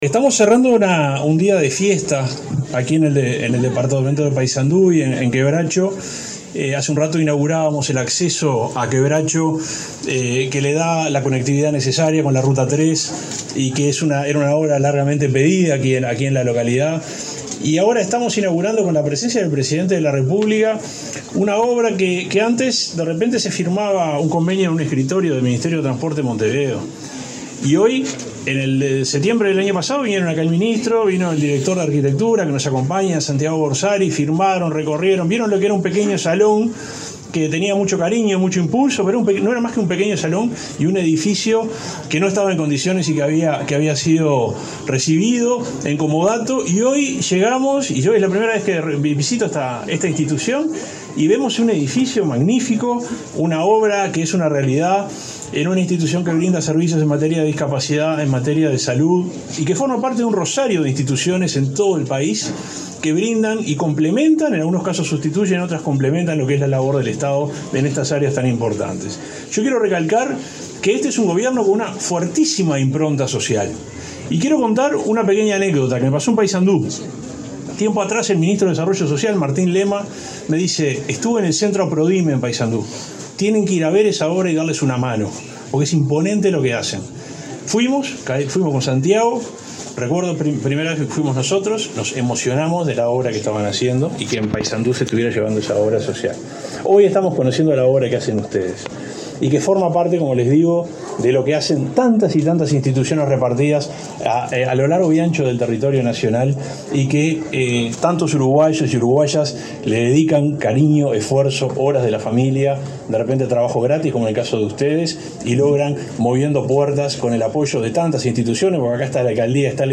El subsecretario de Transporte, Juan José Olaizola, se expresó en la inauguración de obras del Instituto Fortaleza de Quebracho, en Paysandú.